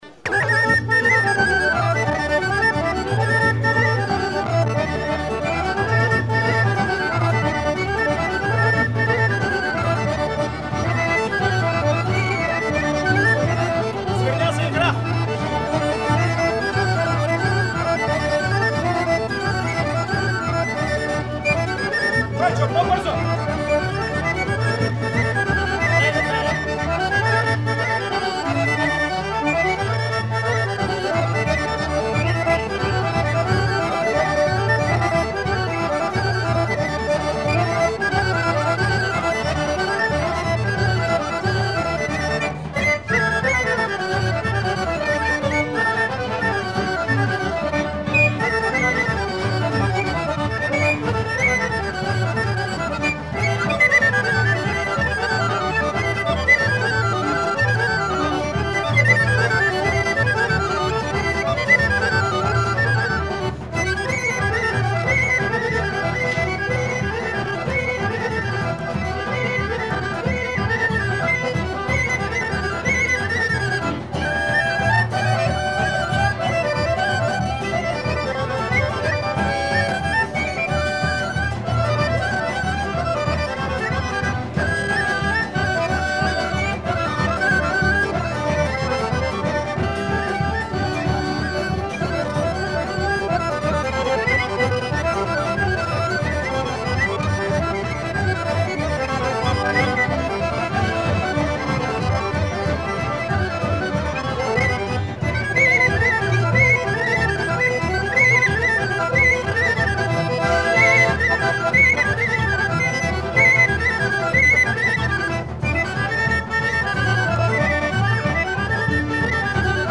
Danse Horo
Pièce musicale inédite